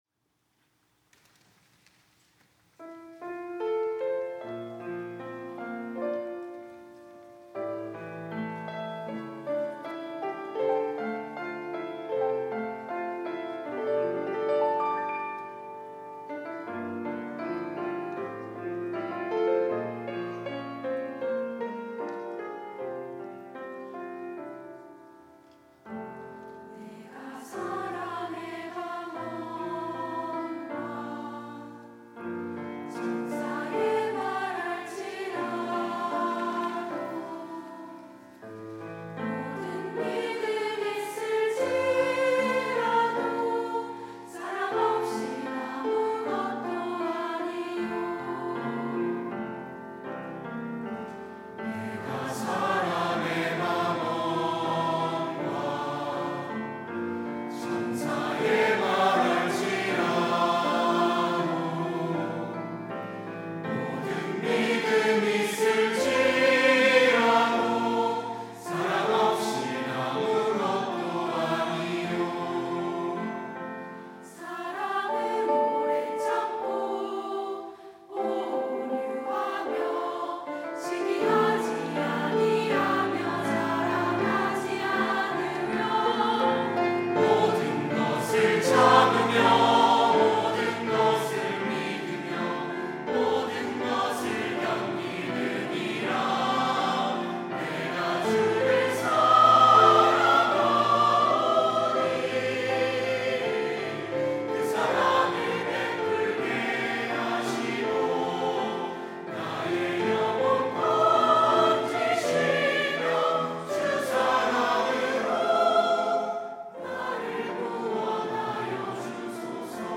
특송과 특주 - 사랑
고등부 에바다 찬양대